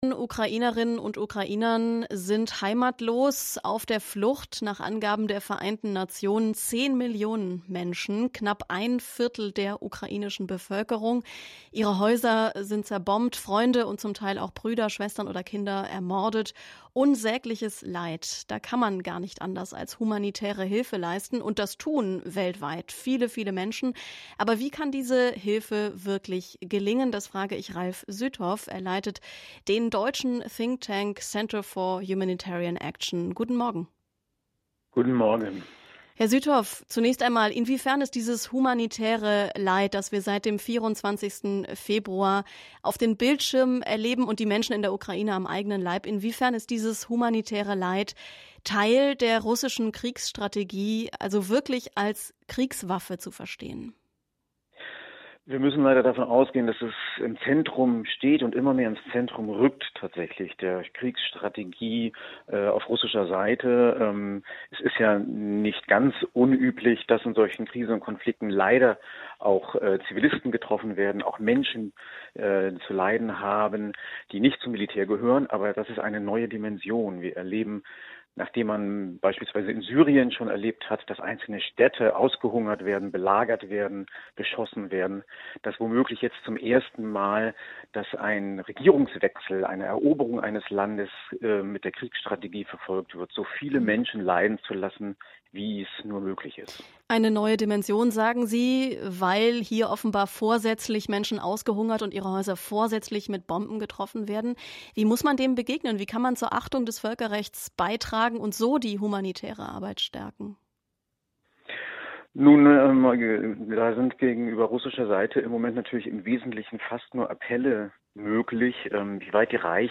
Recording of the radio programme “SWR2 am Morgen” from 22 March 2022: